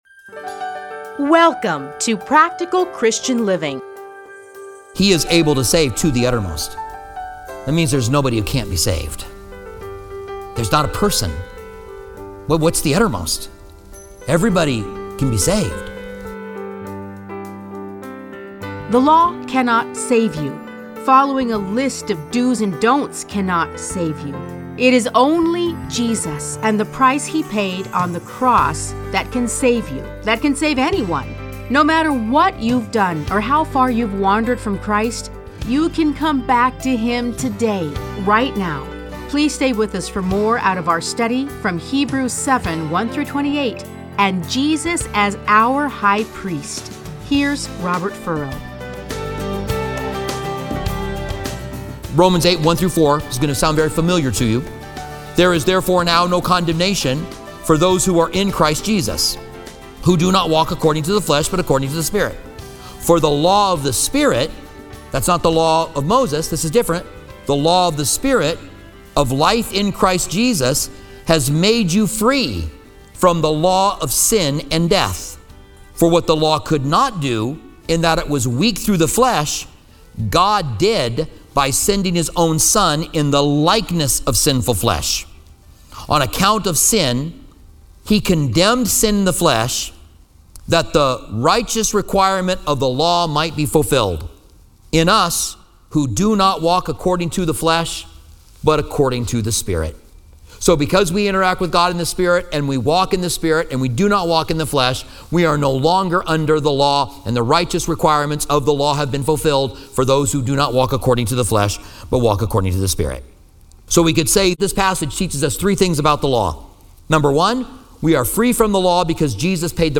Listen to a teaching from Hebrews 7:1-28.